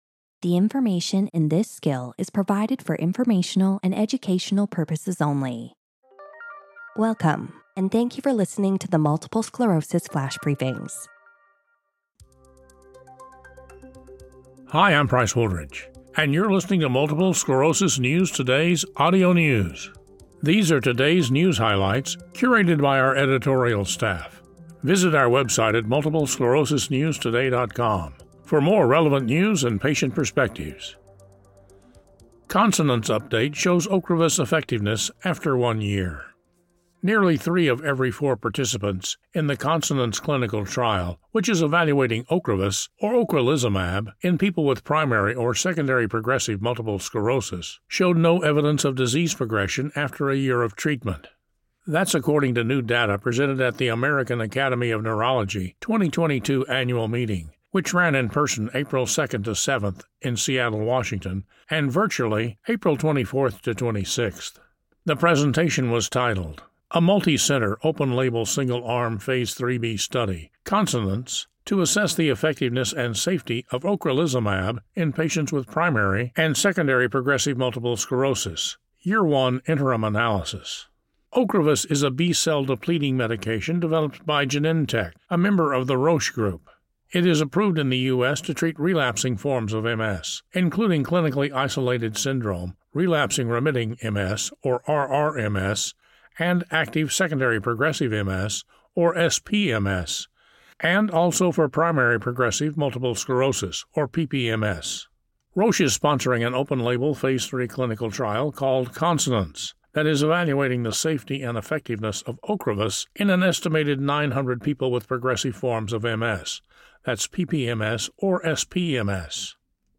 reads a news article about how 75% of SPMS and PPMS patients in the CONSONANCE trial showed no evidence of disease progression after a year of Ocrevus treatment.